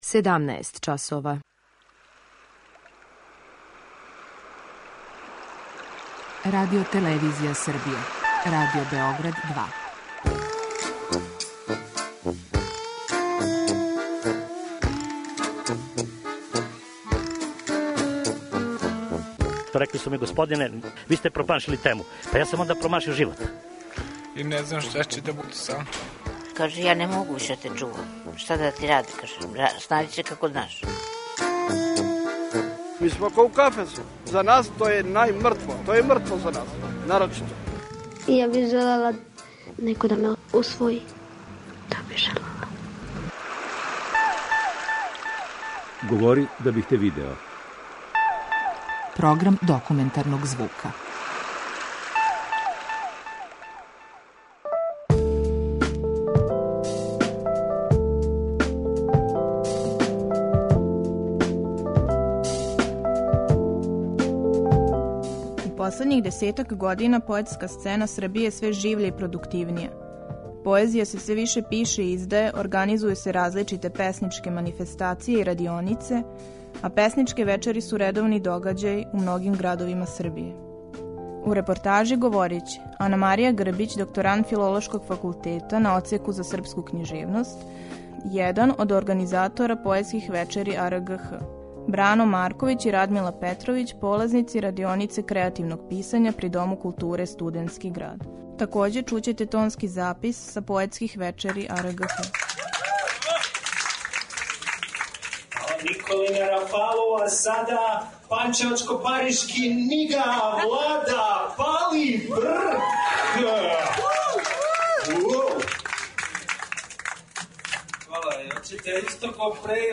Документарни програм
Слушаоци ће такође чути тонски запис са поетских догађаја "АРГХ!".